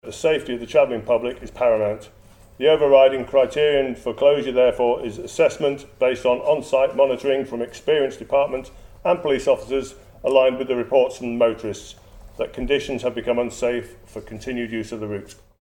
Mr Crookall says his priority is to ensure drivers are not exposed to unnecessary risk: